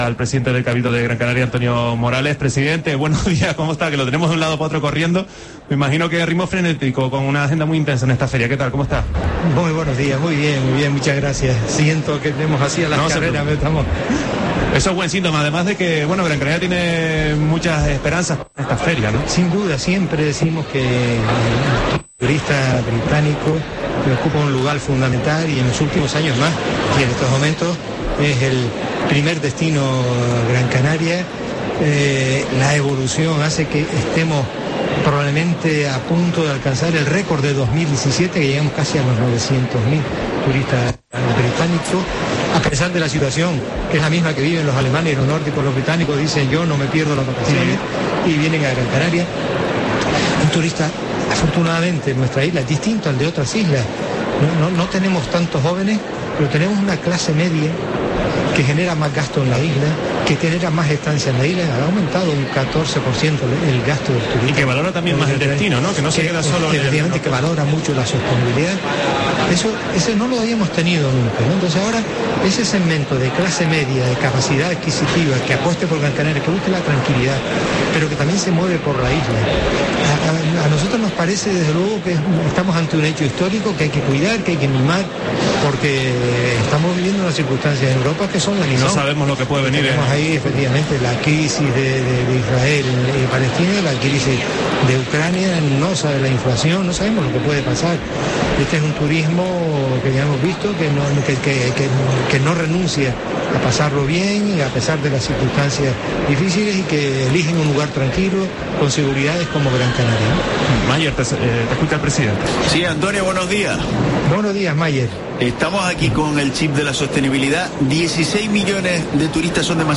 Entrevista a Antonio Morales, presidente del Cabildo de Gran Canaria, en la World Travel Market